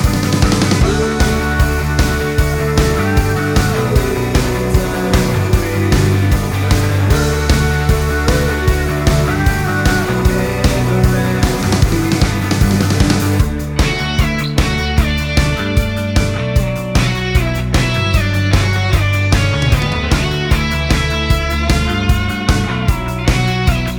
no Backing Vocals Rock 3:03 Buy £1.50